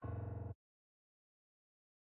CHOMPStation2/sound/piano/E#1.ogg